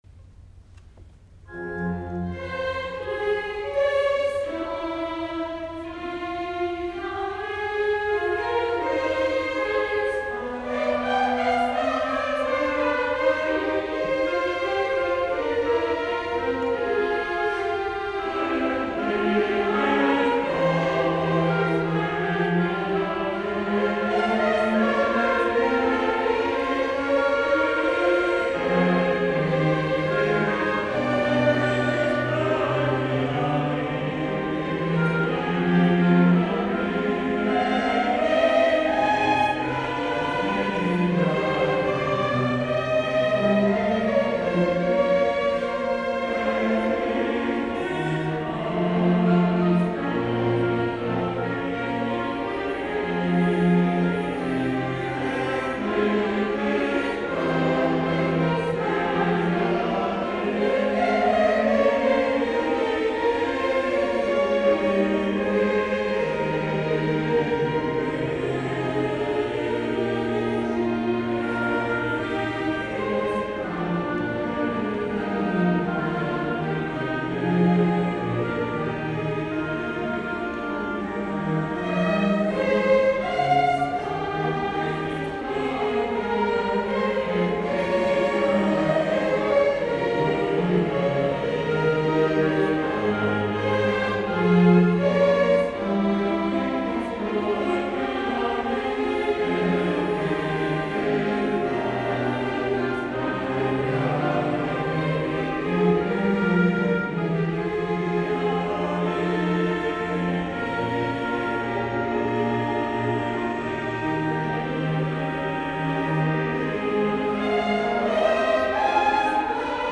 S. Gaudenzio church choir Gambolo' (PV) Italy
Chiesa Parrocchiale - Gambolò
Concerto di Natale